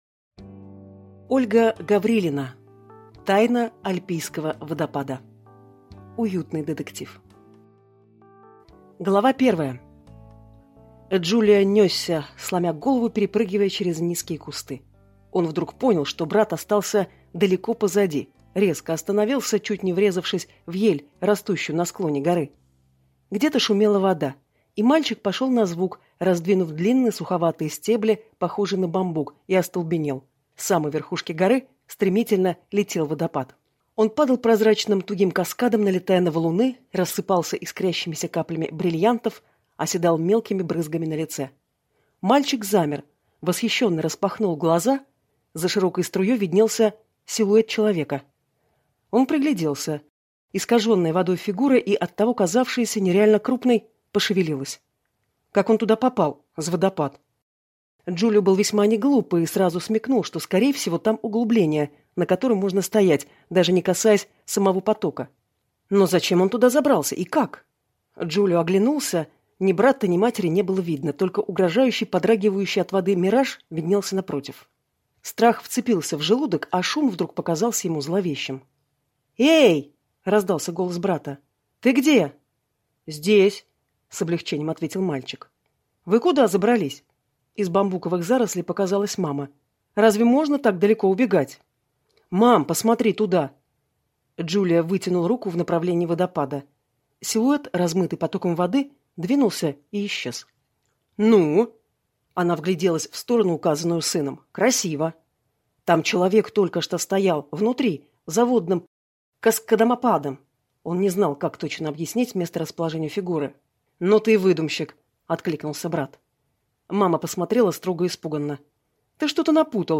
Аудиокнига Тайна альпийского водопада | Библиотека аудиокниг